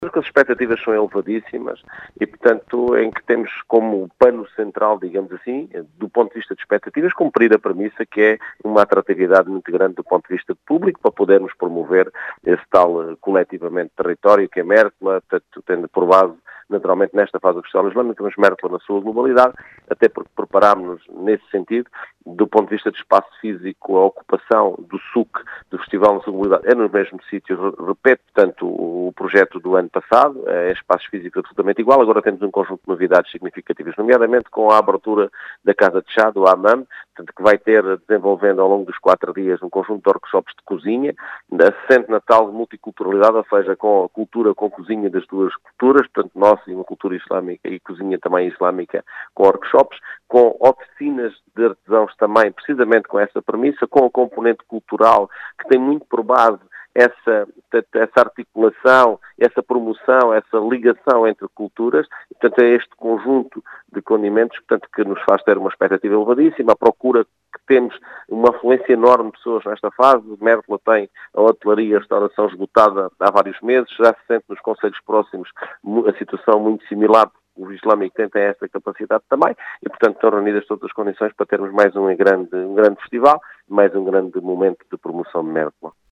Em declarações à Rádio Vidigueira, Mário Tomé, presidente da Câmara de Mértola, afirma que as expectativas são “elevadíssimas”, revelando algumas novidades nesta edição, bem como a elevada procura por parte dos visitantes.
Mario-Tome.mp3